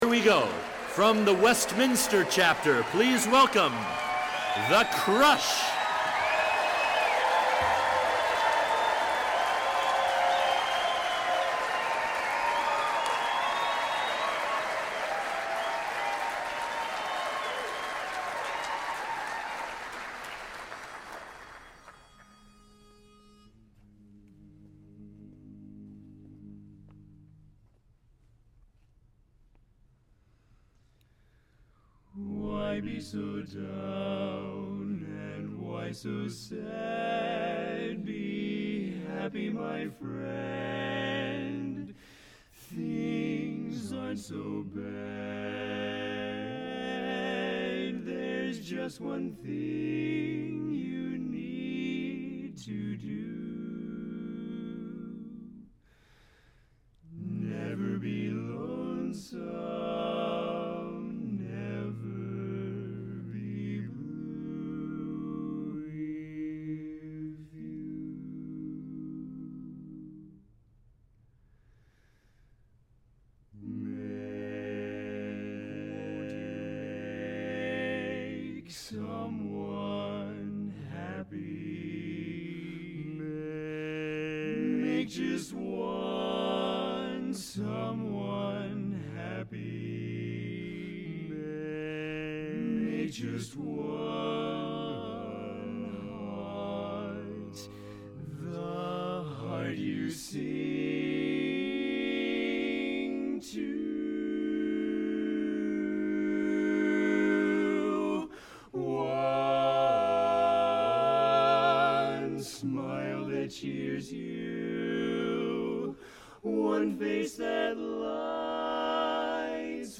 tight, bright harmonies and youthful, toe-tapping energy